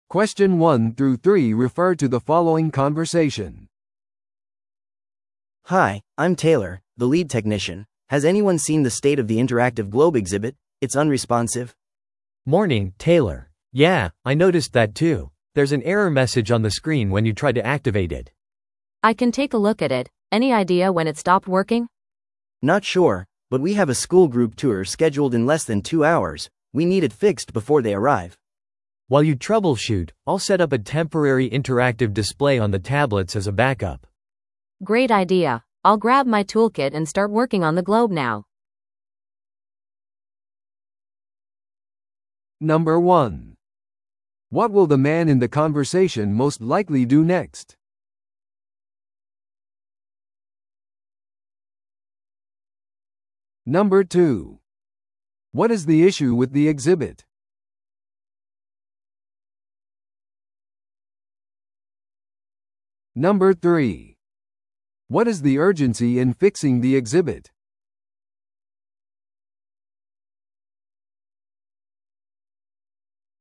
No.1. What will the man in the conversation most likely do next?